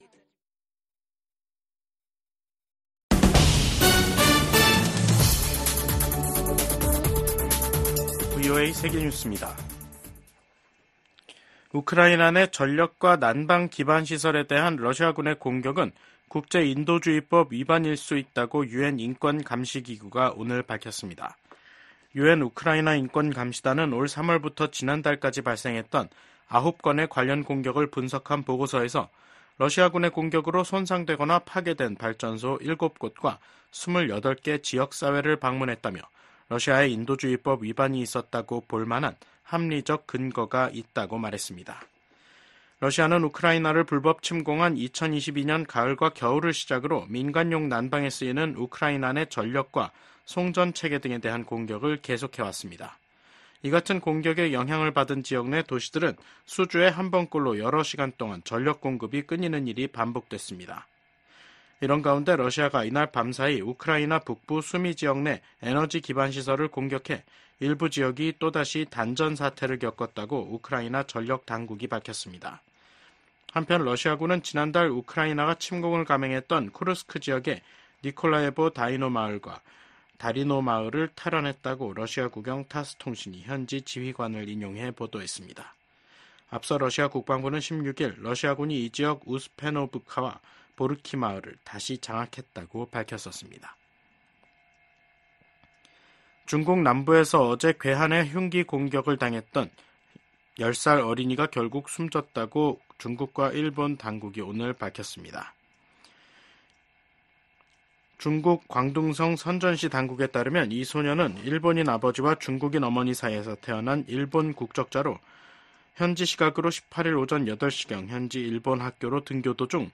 VOA 한국어 간판 뉴스 프로그램 '뉴스 투데이', 2024년 9월 19일 3부 방송입니다. 북한은 고중량 고위력의 재래식 탄두를 장착한 신형 단거리 탄도미사일 시험발사에 성공했다고 밝혔습니다. 미국 국무부 부장관이 북한과 러시아의 협력이 북한으로 하여금 더 도발적인 행위를 하도록 부추길 우려가 있다고 말했습니다. 유럽연합과 영국 프랑스, 독일 등 유럽 주요국이 엿새 만에 재개된 북한의 미사일 발사를 강력하게 규탄했습니다.